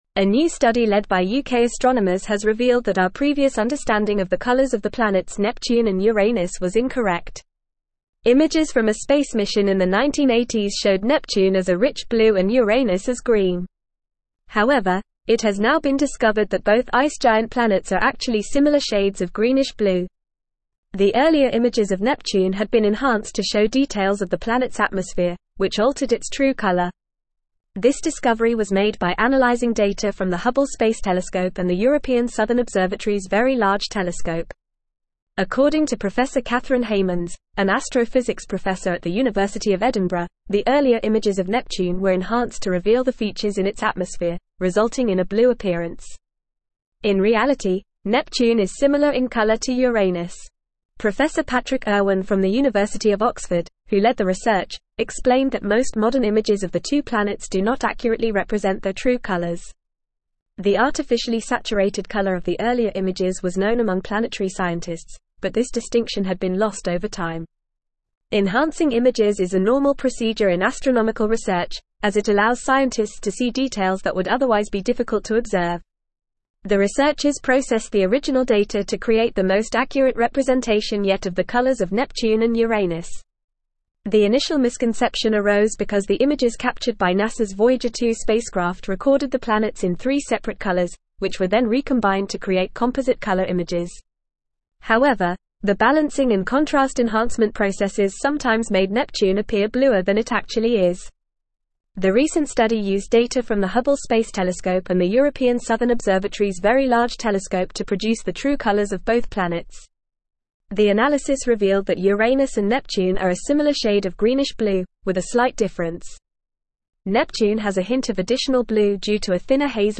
Fast
English-Newsroom-Advanced-FAST-Reading-True-Colors-of-Neptune-and-Uranus-Revealed.mp3